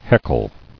[heck·le]